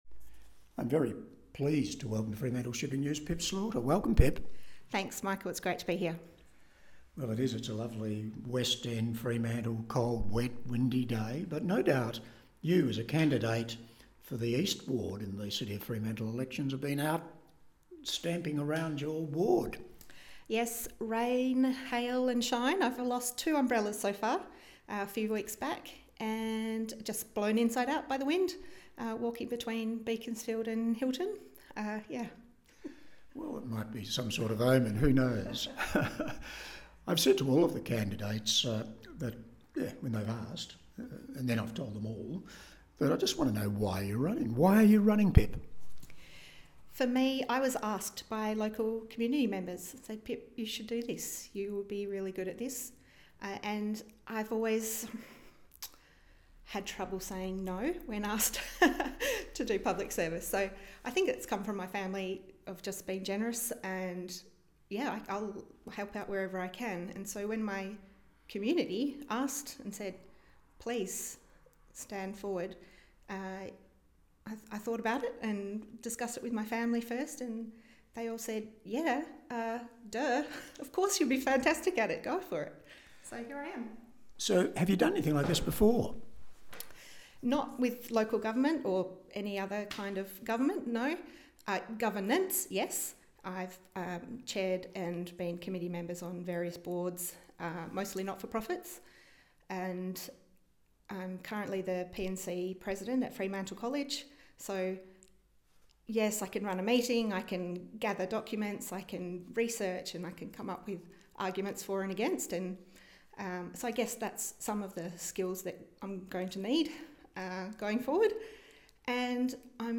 The East Ward Candidates Interviews